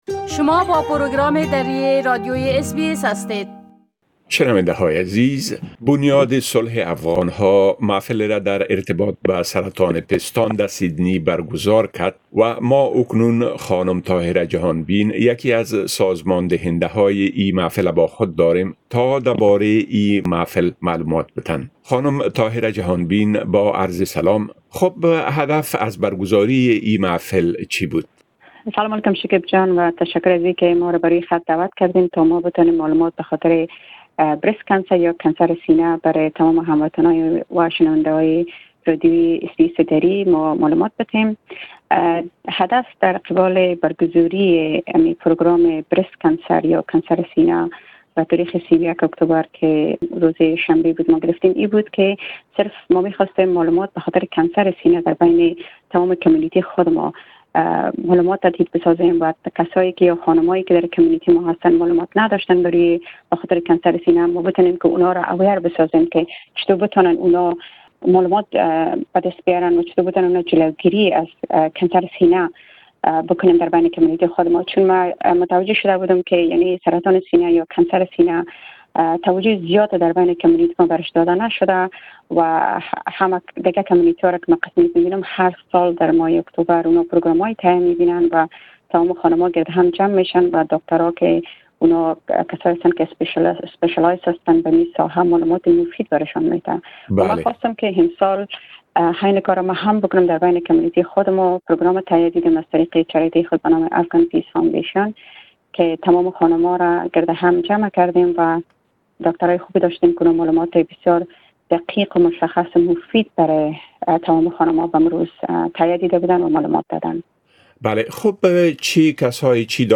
دربارۀ معلوماتى كە در جريان آن پيرامون اين بيمارى ارائە شد مصاحبە كردە ايم